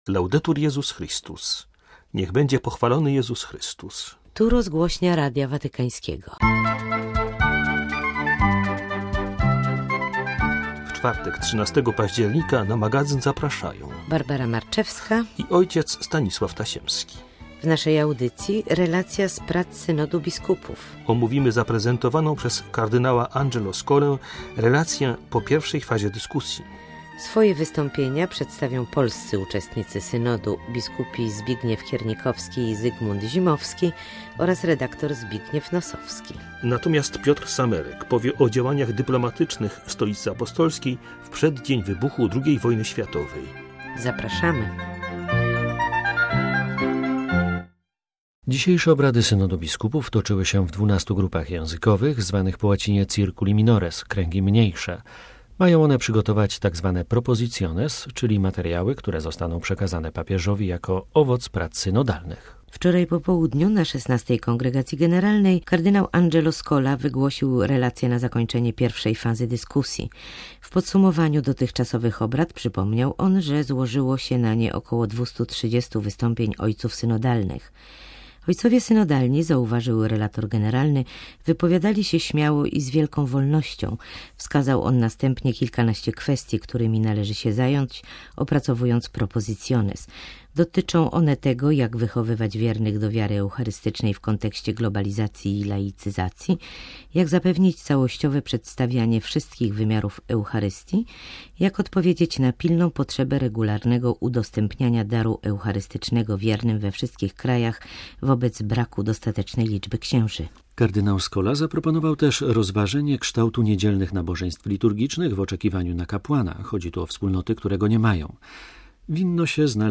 relacja kard. Angelo Scoli po pierwszej fazie dyskusji; - wystąpienia polskich uczestników Synodu